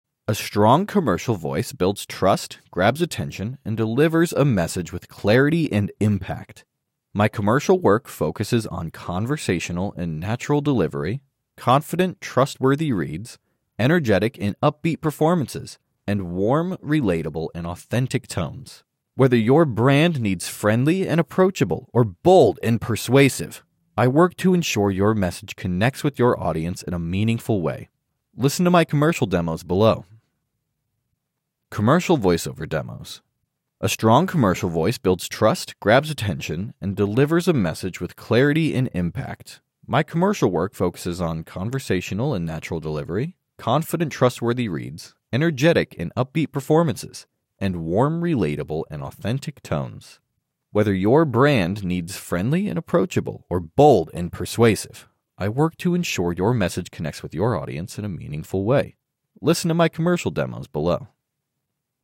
Commercial
Voiceover Demos
• Conversational and natural delivery
• Confident, trustworthy reads
• Energetic and upbeat performances
• Warm, relatable, and authentic tones